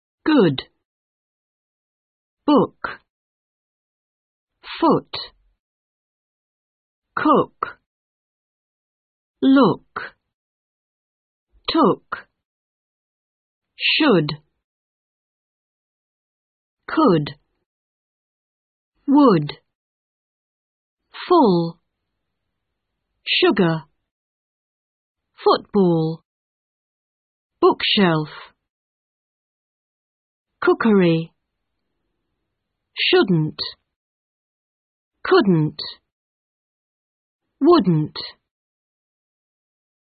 British English